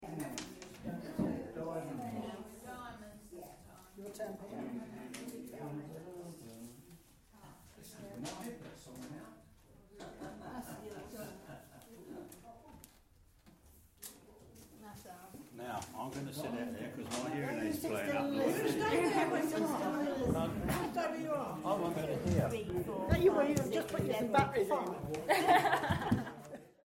Sutton St James Good Companions group, Sutton St James Village Hall.